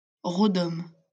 Rodome (French pronunciation: [ʁɔdɔm]